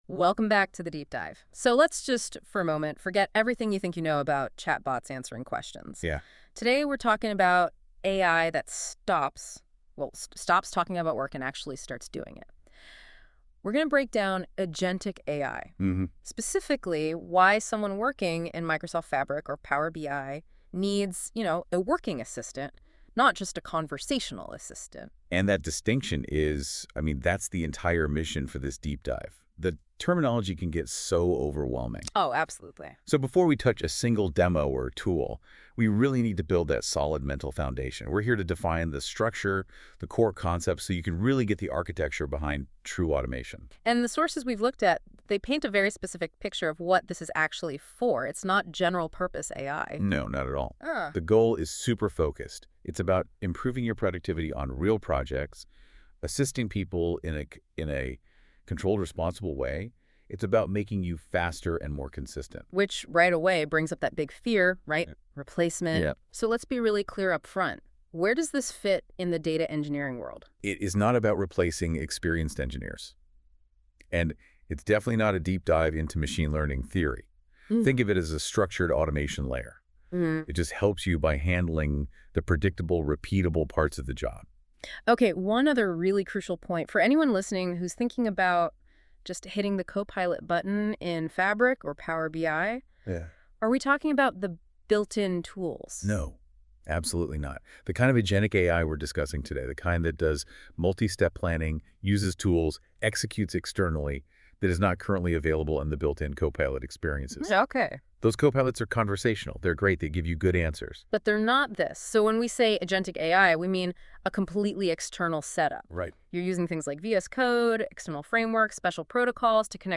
If you like to listen to the content on the go, here is the AI generated podcast explaining everything about this blog 👇.